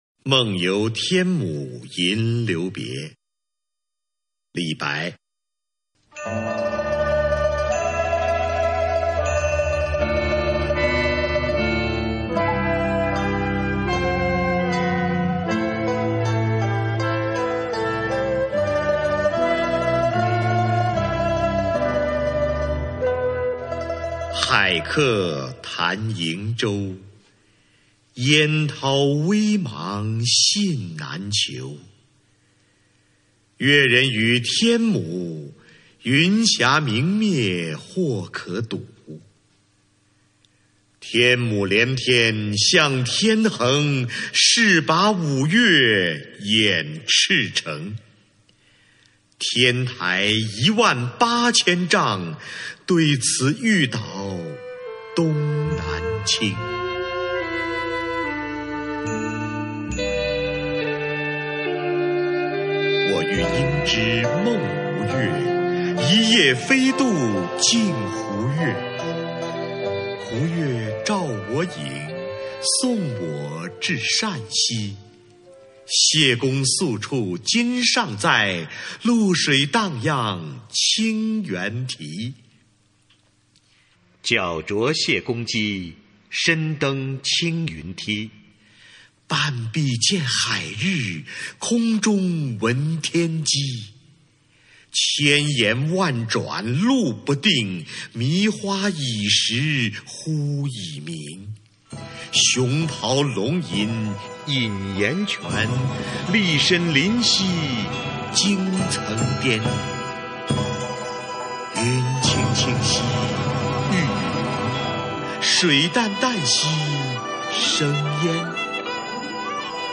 梦游天姥吟留别 李白 经典朗诵欣赏群星璀璨：中国古诗词标准朗读（41首） 语文PLUS